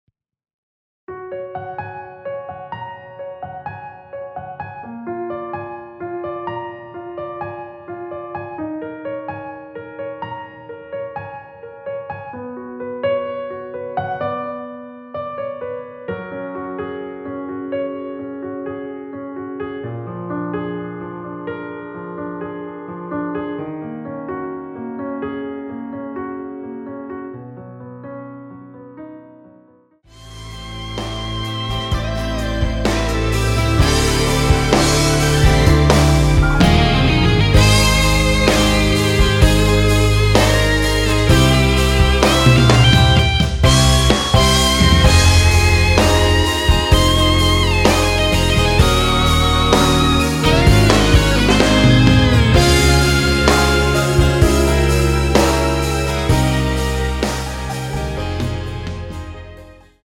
원키에서(+4)올린 MR입니다.
F#
앞부분30초, 뒷부분30초씩 편집해서 올려 드리고 있습니다.